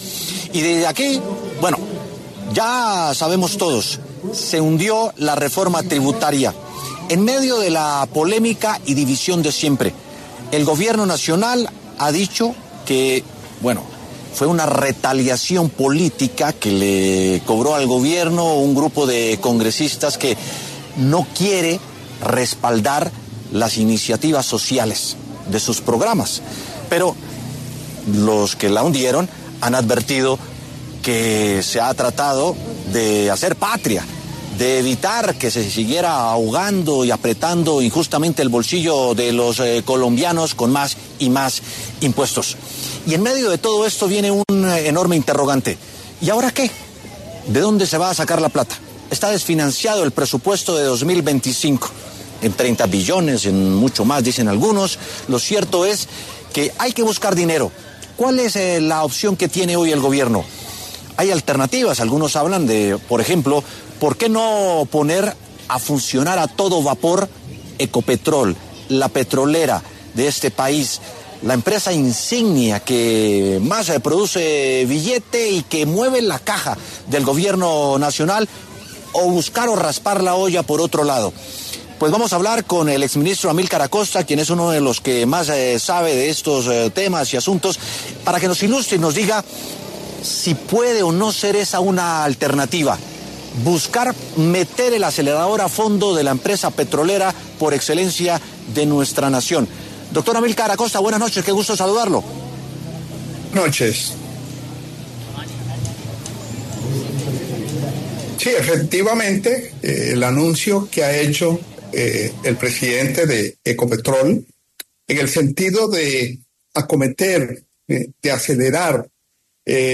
El exministro de Minas Amylcar Acosta habló en W Sin Carreta y analizó la coyuntura económica que dejó el hundimiento de la reforma tributaria.